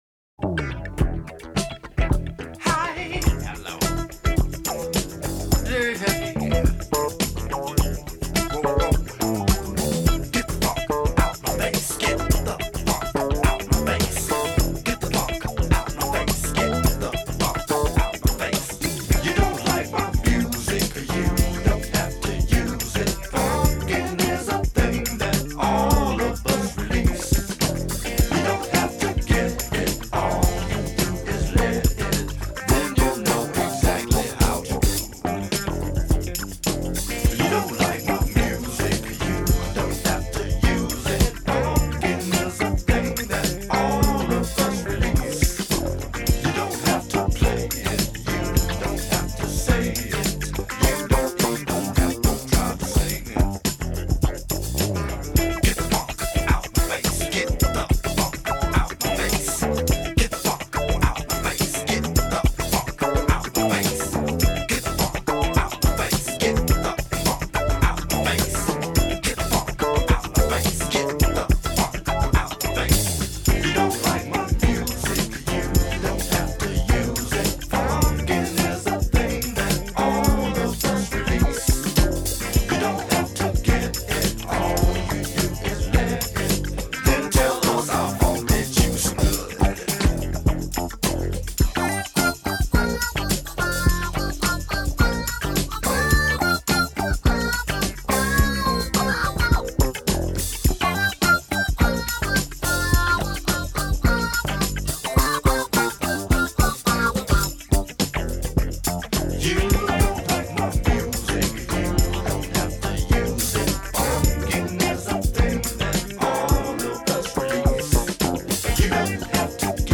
groovy R&B/soul songs
Disco Version